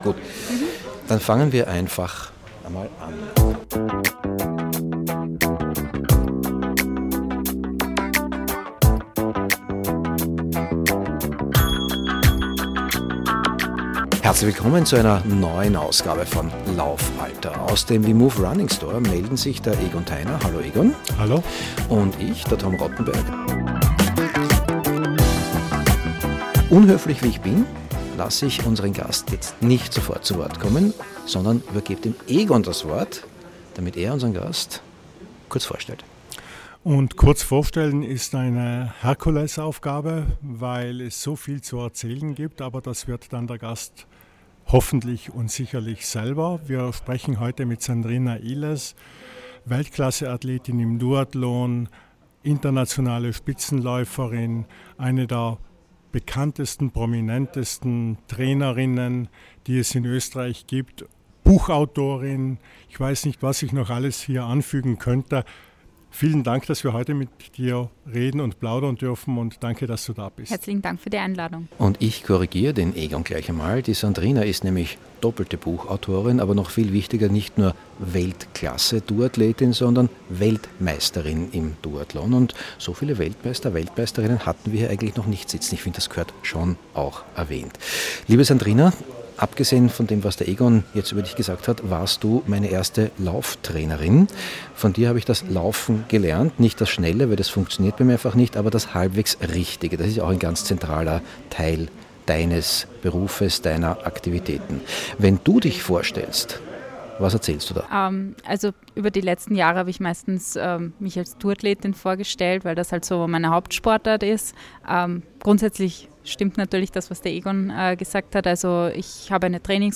Im WEMOVE Runningstore in Wien Mitte sprechen wir diesmal mit einer der prominentesten und bekanntesten Personen in der Welt des österreichischen Lauf- und Ausdauersports